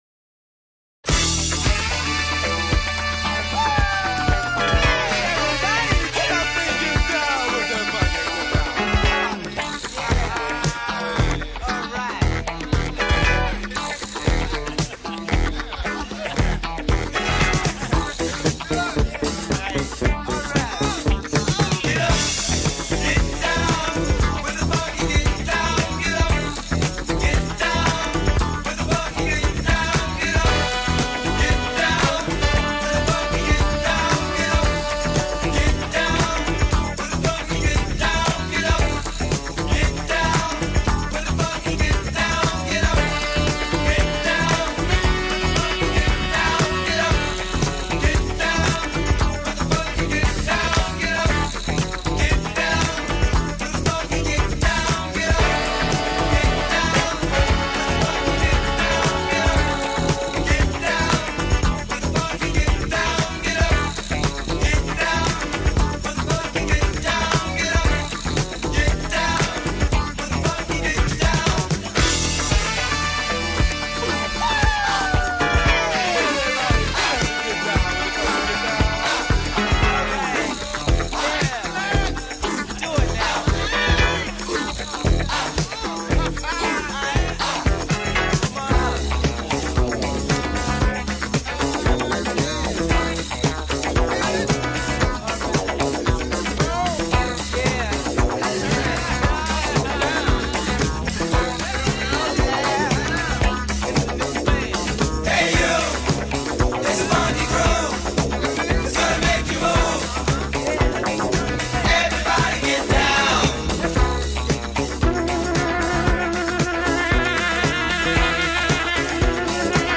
Check out the double entendre while you “get down” to the groove!